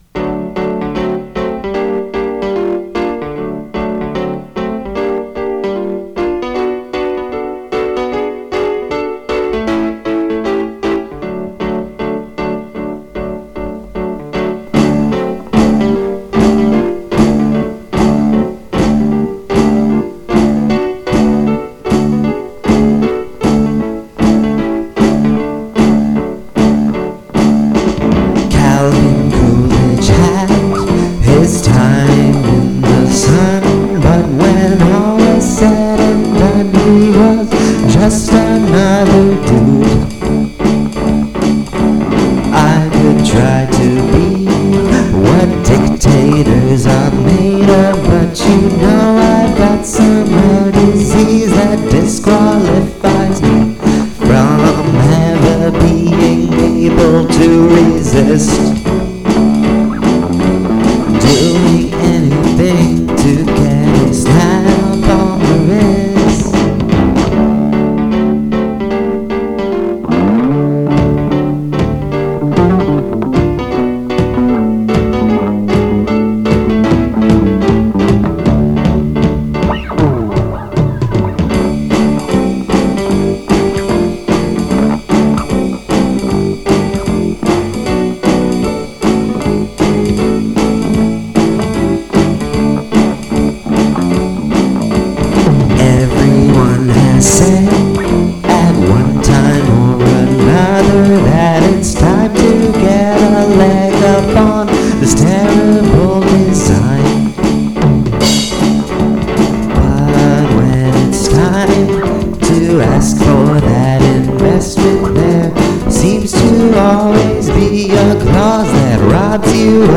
prog rock keyboard solos and guitar flute